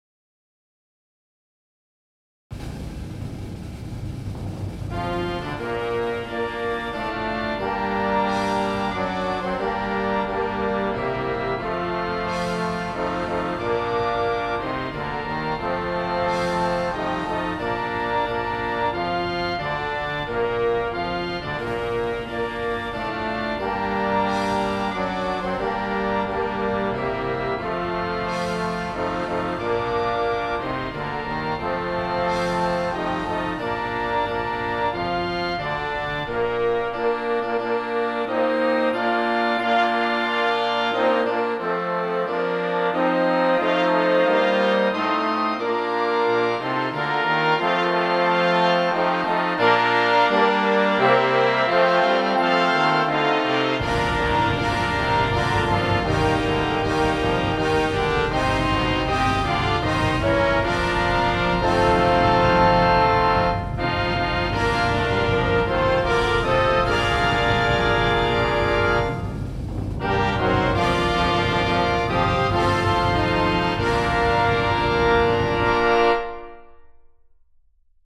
This would be playable by advanced bands and musicians.